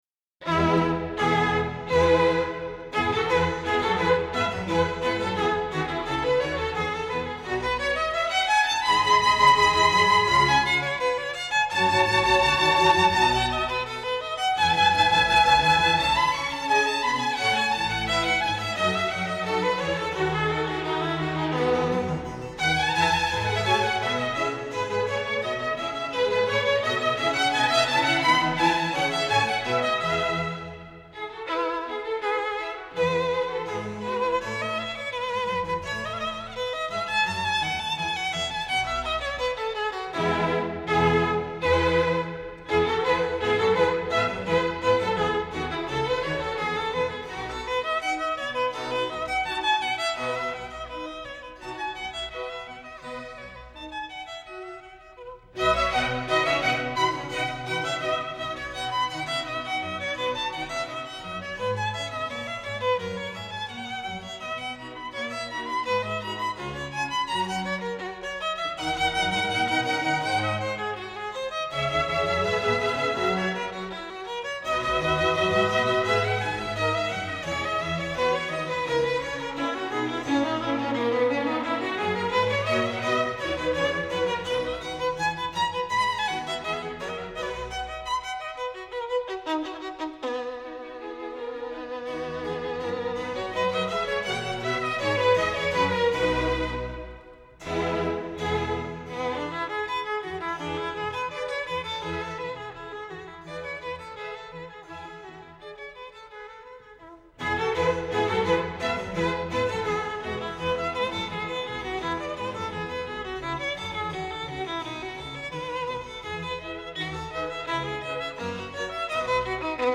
Classical, Contemporary, Baroque